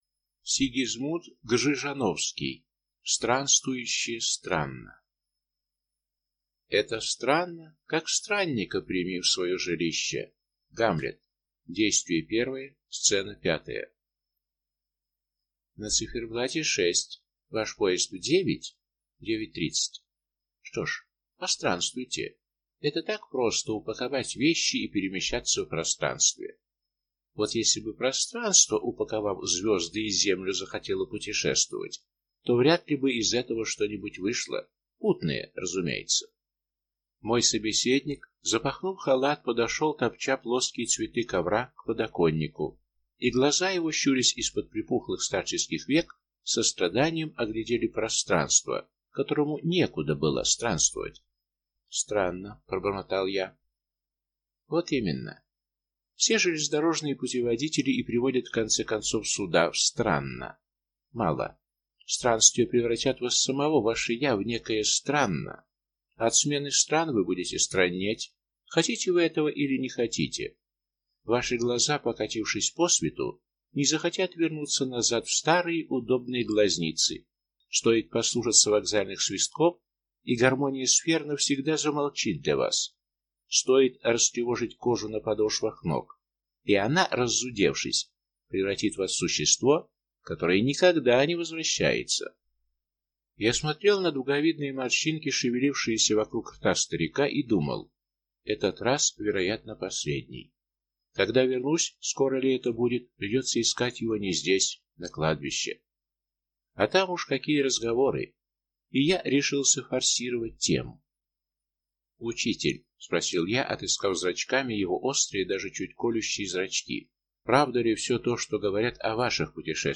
Аудиокнига Странствующее «Странно» | Библиотека аудиокниг